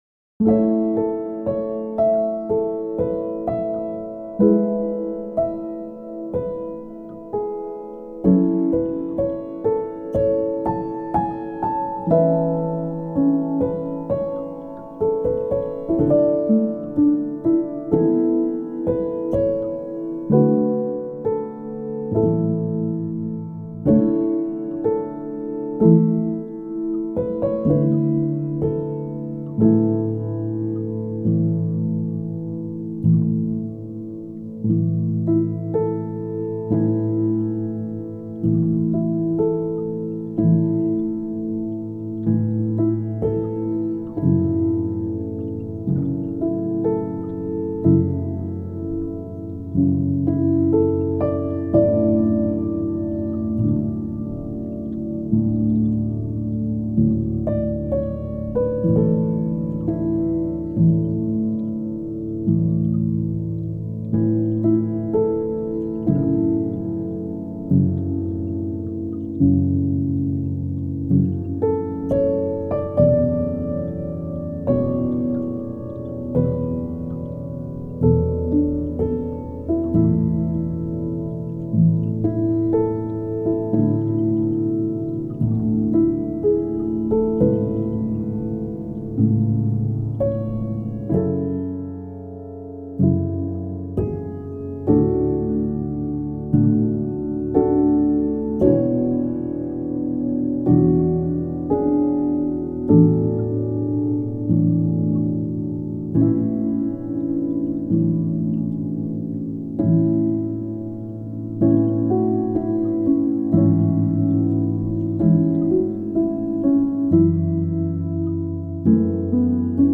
ピアノ 寝落ち 穏やか